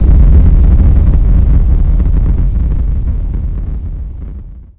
rumbleoff.wav